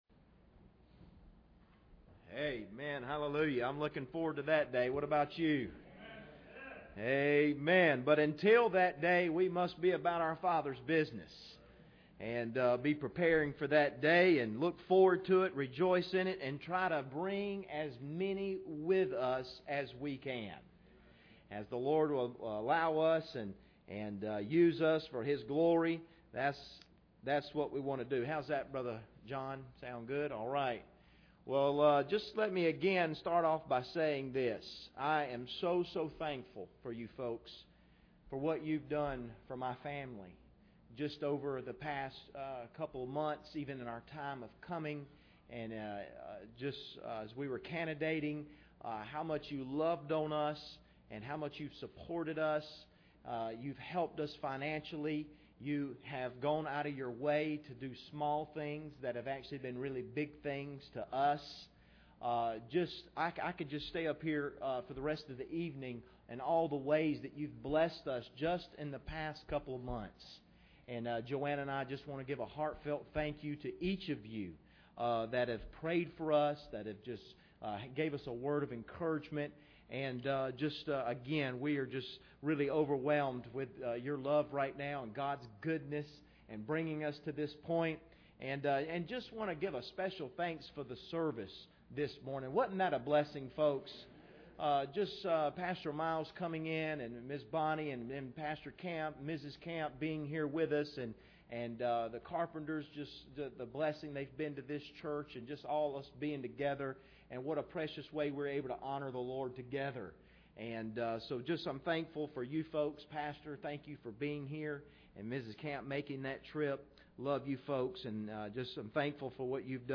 Proverbs 16:9 Service Type: Sunday Evening Bible Text